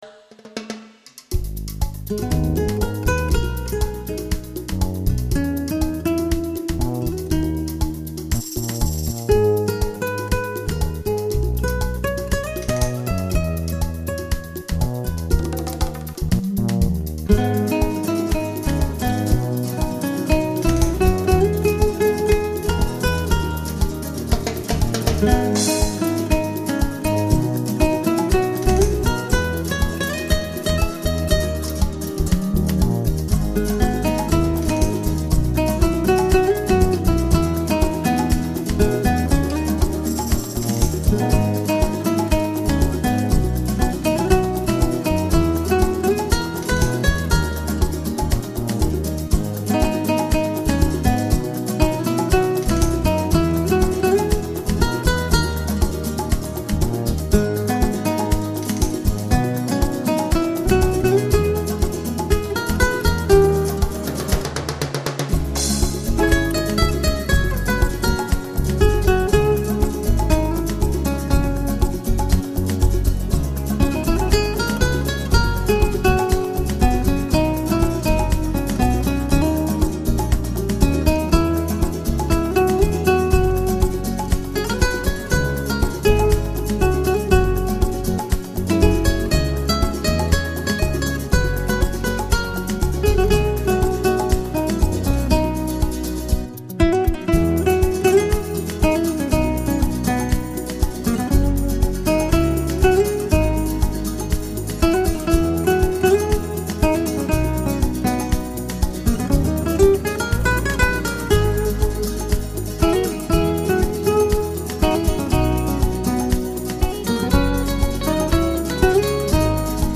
唱片着重音乐自身的整体效果而非音响效果。但是吉他声音也录得通透、清澈、传真和富有空气感，把乐意表现得细致入微、丝丝入扣。